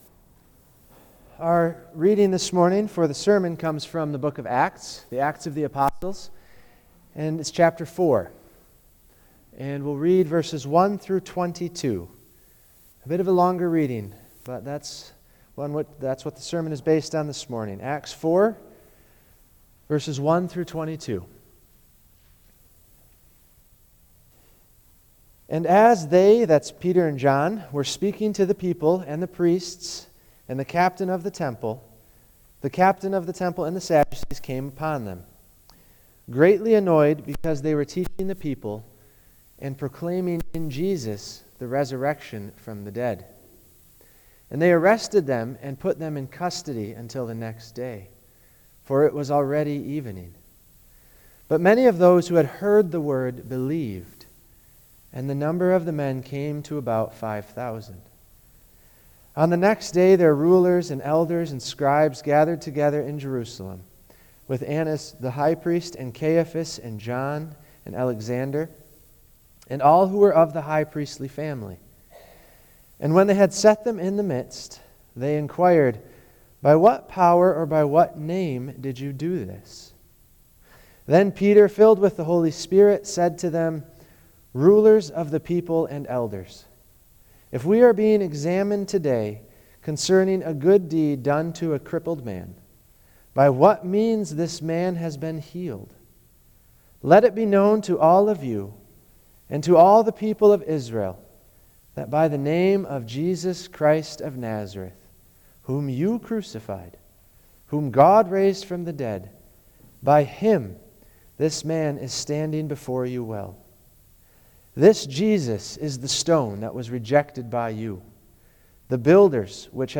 Salvation Series Various Sermons Book Acts Watch Listen Save In Acts 4:1–22, Peter and John are arrested for teaching the people and boldly proclaiming in Jesus the resurrection from the dead.